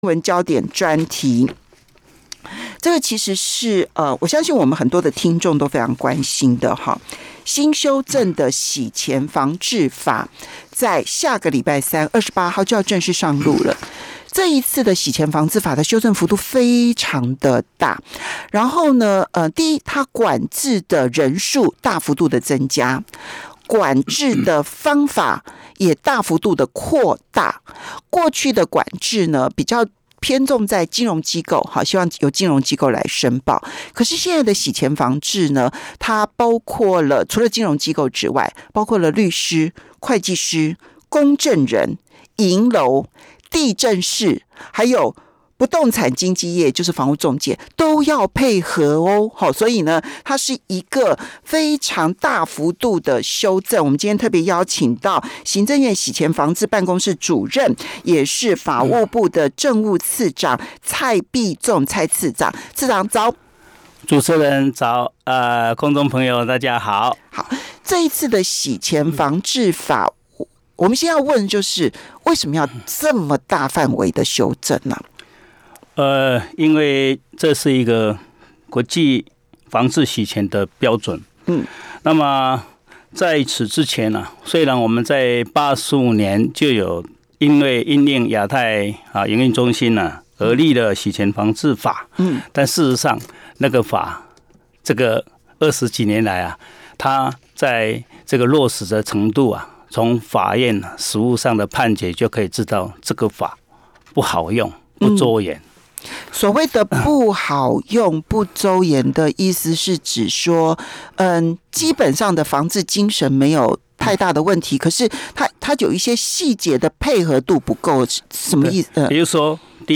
專訪內容語音檔1.mp3 (另開新視窗)